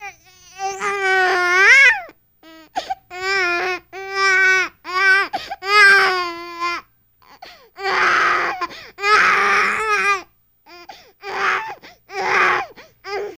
Baby Squealing and Complaining